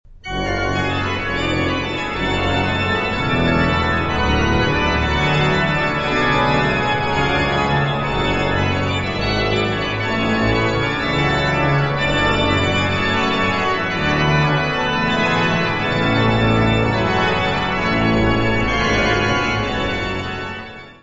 Organ works
Notas:  Este disco foi gravado ao vivo na Basilica de St. Alexander und Theodor, Benediktinerabtei em Ottobeuren na Alemanha, durante o mês de Maio de 1998; O orgão utilizado na gravação foi construído por Johann Andreas Silbermann, entre 1756-1761; No do Serviço de Aquisições e Tratamento Técnico